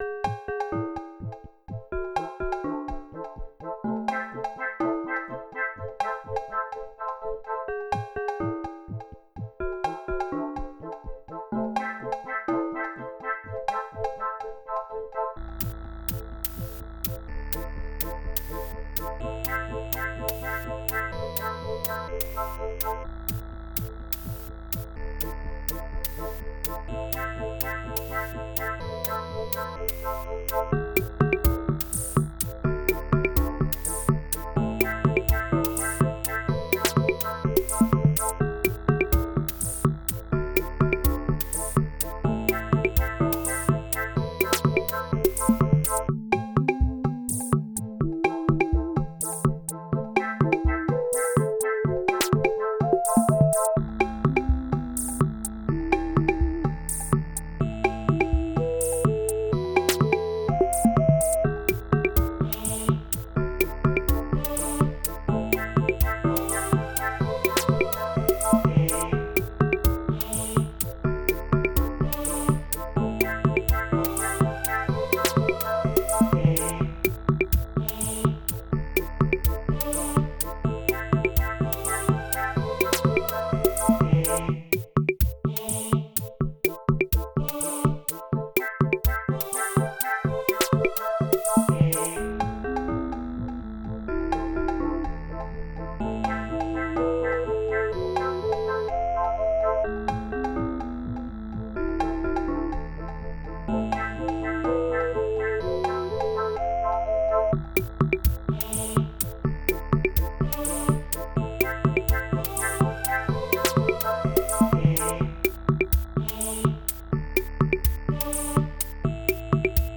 Genre: Techno.